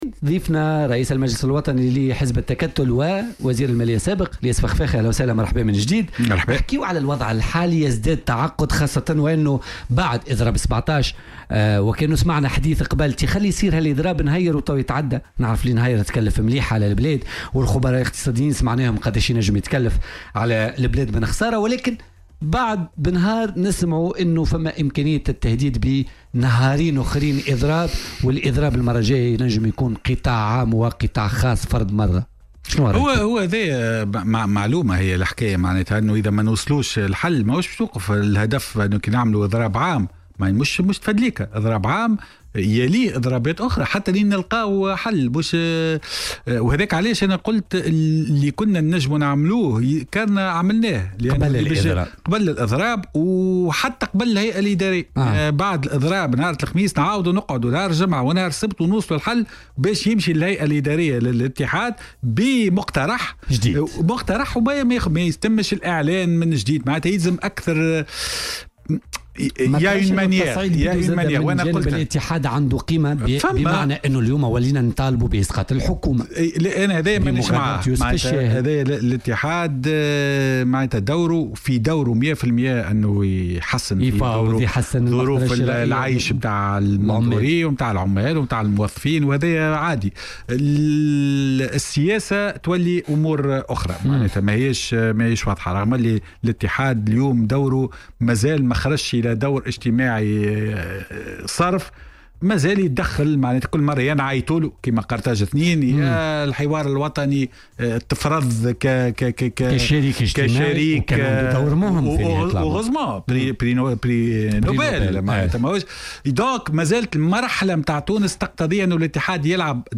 أكد رئيس المجلس الوطني لحزب التكتل الياس الفخفاخ ضيف بولتيكا اليوم الإثنين أن يوسف الشاهد قام بأشياء ايجابية من موقعه كرئيس حكومة و تحمل مسؤوليته في الحكومة واحترم الدستور في الصلاحيات الموكولة له.